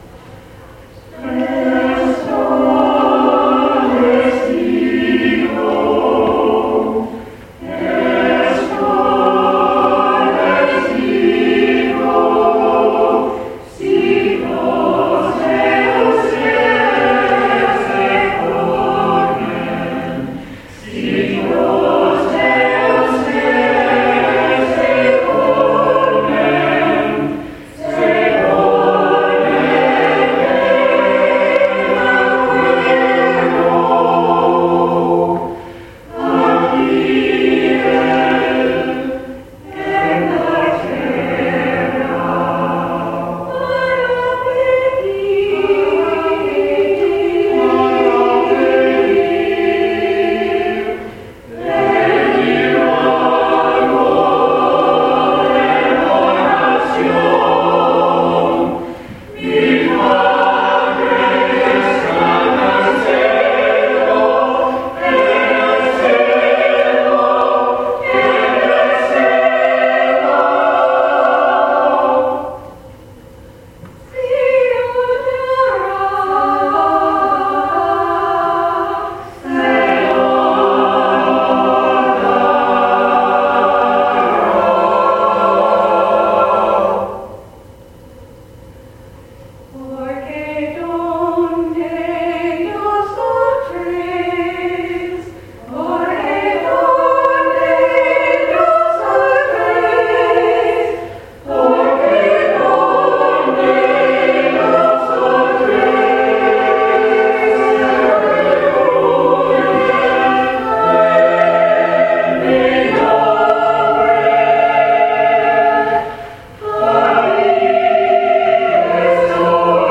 MCC Senior Choir Esto Les Digo February 22, 2015 Anthem Download file Esto Les Digo